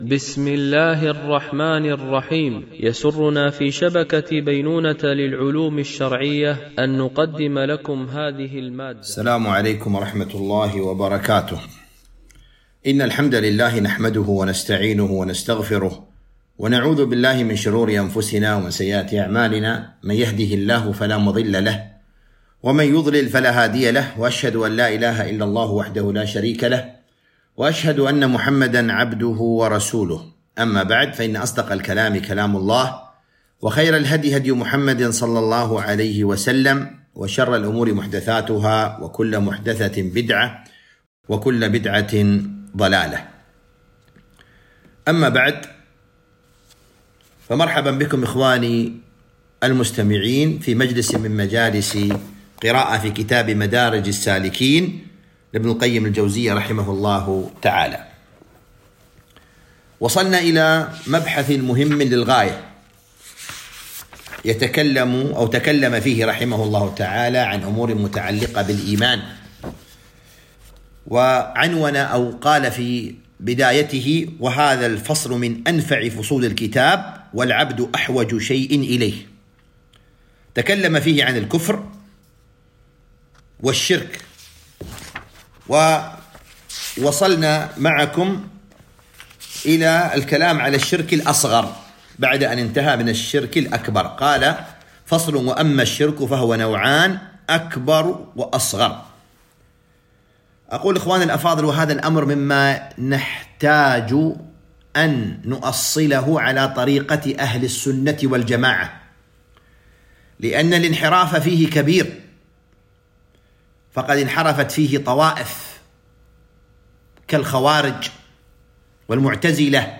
قراءة من كتاب مدارج السالكين - الدرس 37